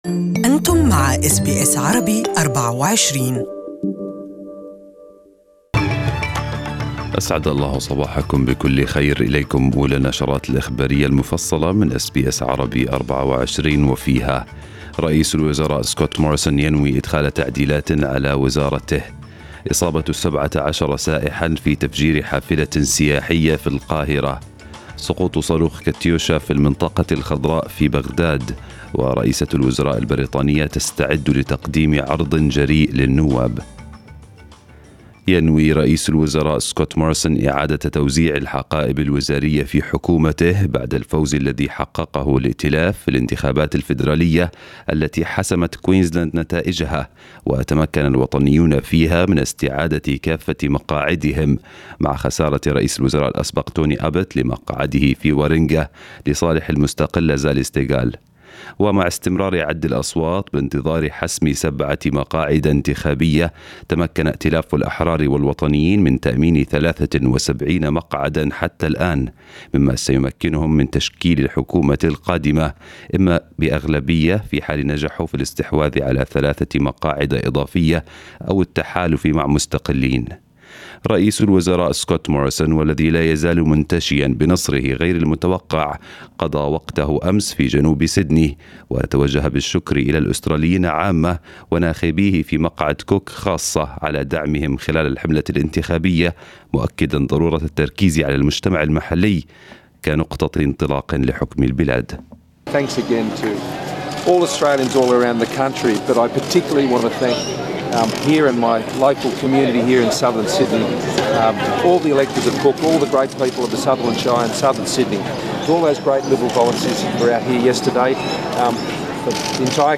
News bulletin in Arabic 20/5/2019